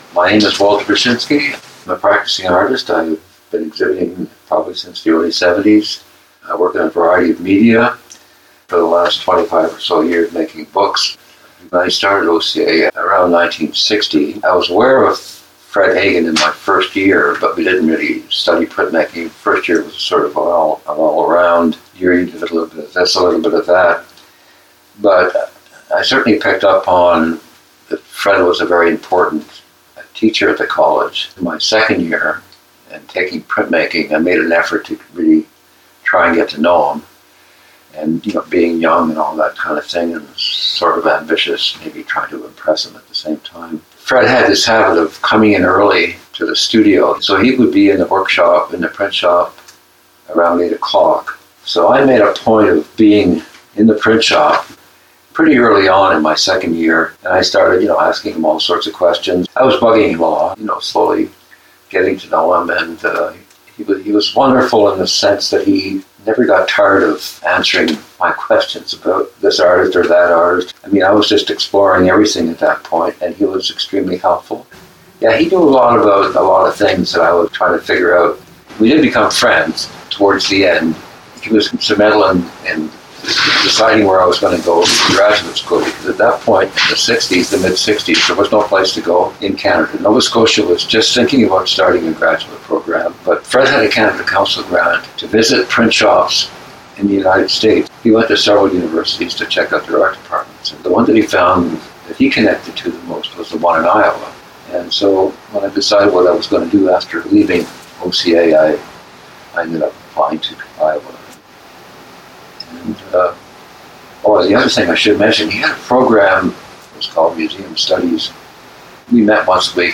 Please note: due to the quality of the audio, this interview has some static and background noise.